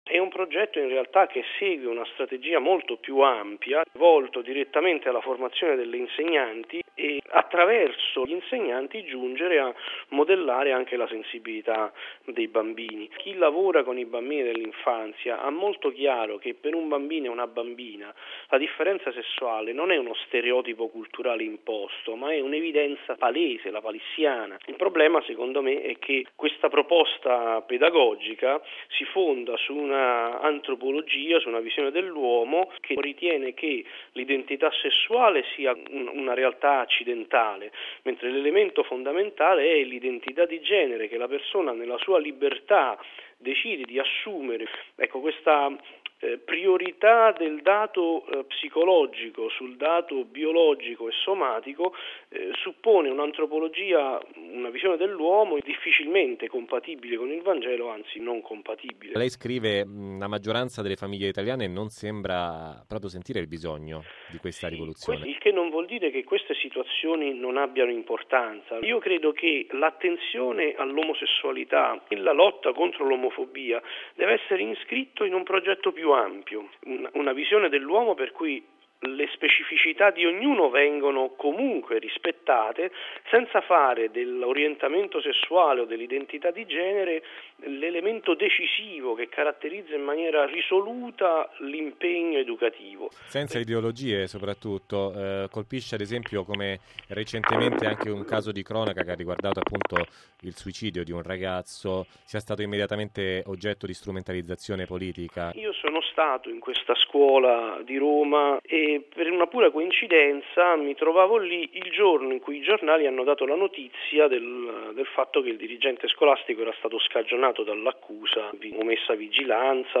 Radiogiornale del 24/02/2014 - Radio Vaticana